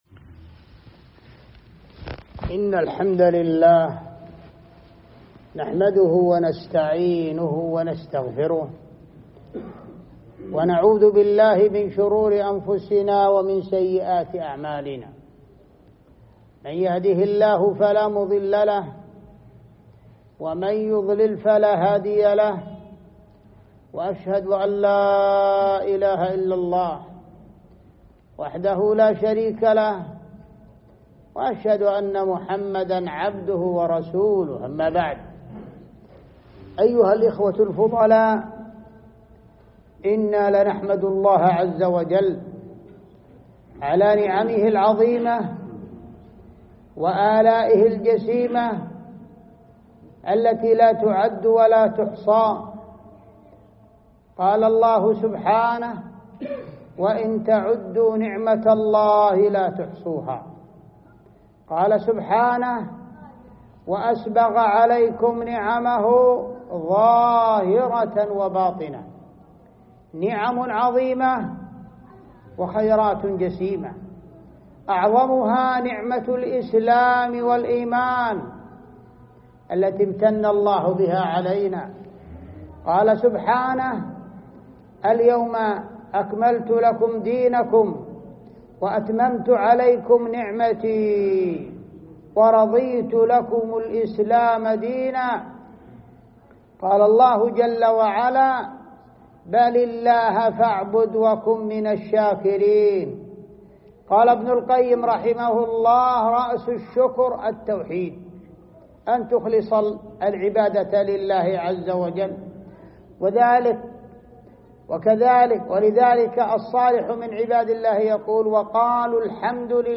محاضرة
مسجد موسى بن نصير بشرورة